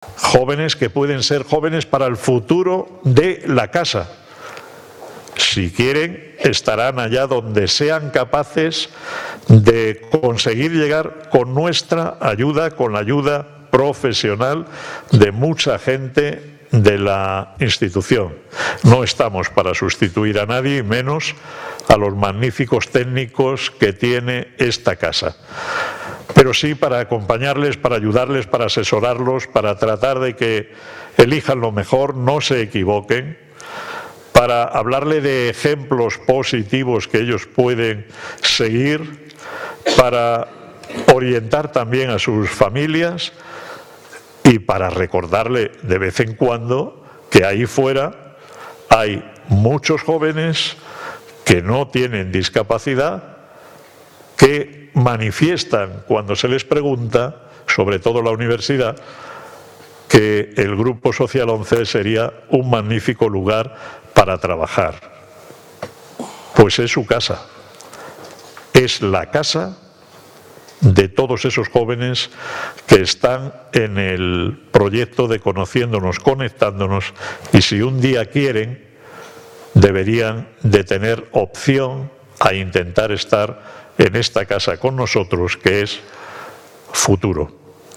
El CCG fue clausurado por el presidente del Grupo Social ONCE, Miguel Carballeda, quien durante su intervención hizo alusión, entre otros temas, a la “buena situación económica” actual del Grupo, aunque apeló a la “prudencia para saber gestiona los éxitos que estamos teniendo y